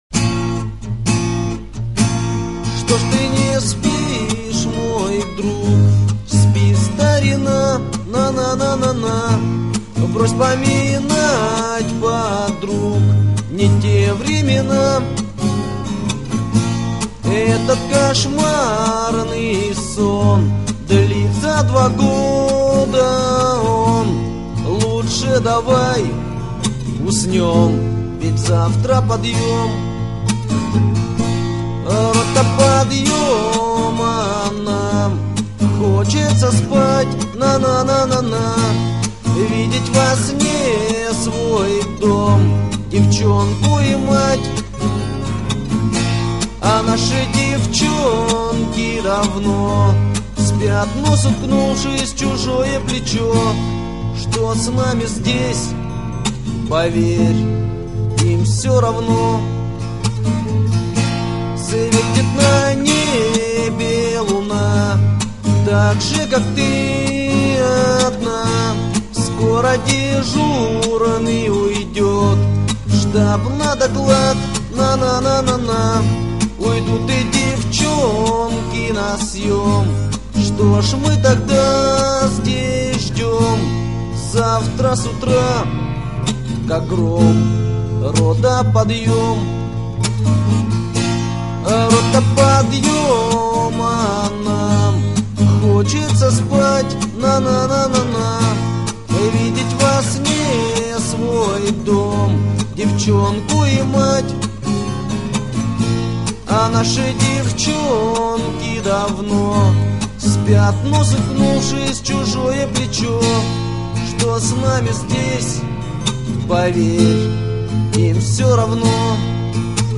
Категория: Песни под гитару